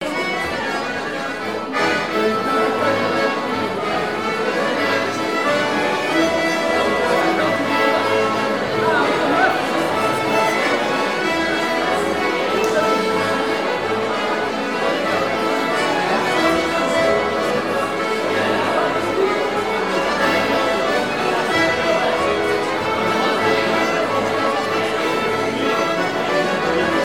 danse : scottich trois pas
Présentation lors de la sortie de la cassette audio
Pièce musicale inédite